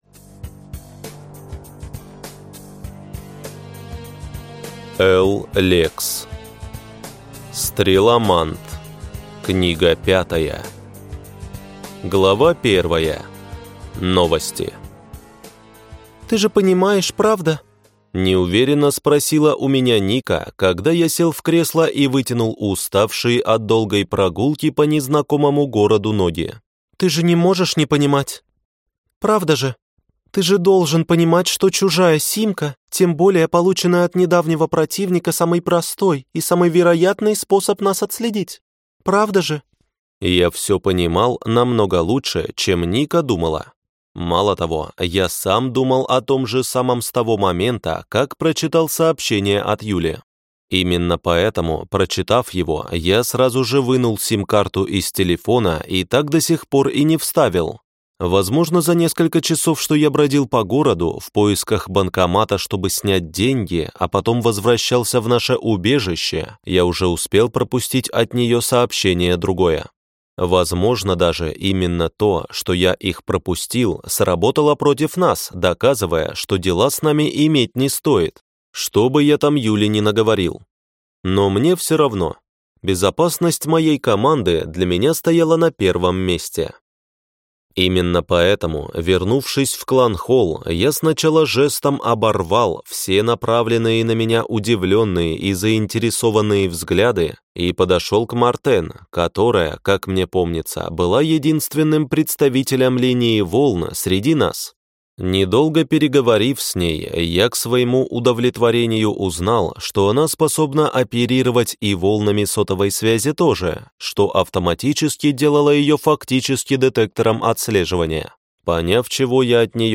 Аудиокнига Стреломант. Книга 5 | Библиотека аудиокниг